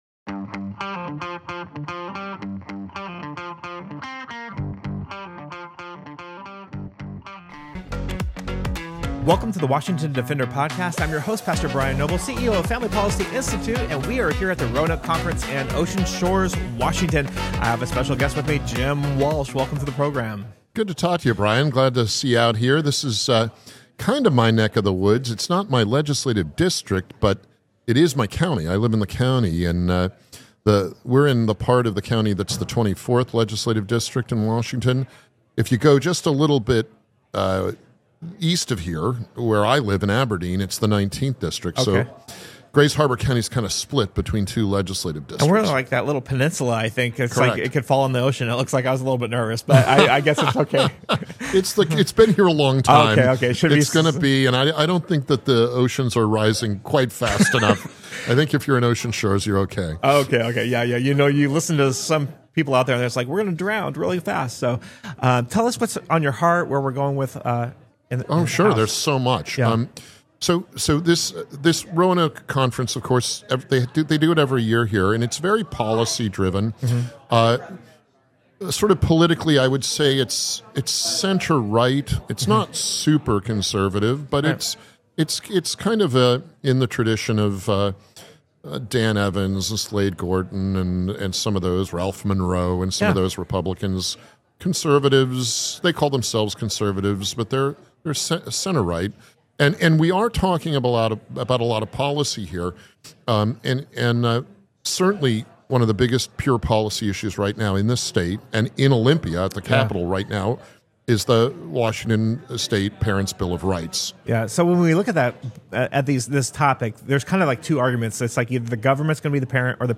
Parent's Bill of Rights Under Attack Interview with Rep. Jim Walsh